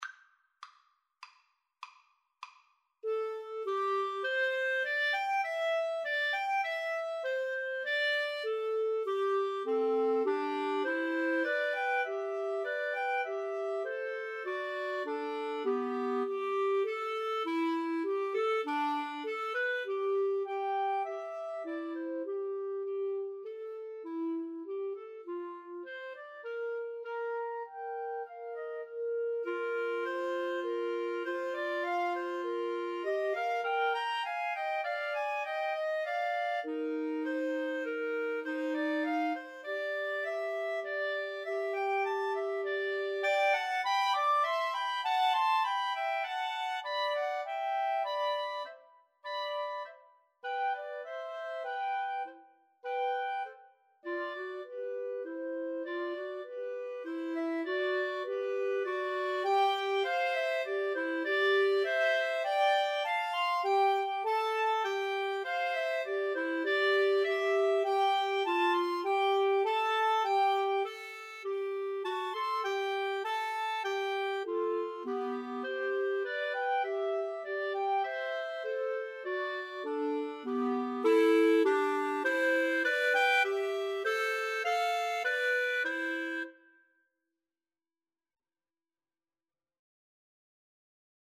C major (Sounding Pitch) D major (Clarinet in Bb) (View more C major Music for Clarinet Trio )
5/4 (View more 5/4 Music)
Allegro guisto (View more music marked Allegro)
Clarinet Trio  (View more Easy Clarinet Trio Music)
Classical (View more Classical Clarinet Trio Music)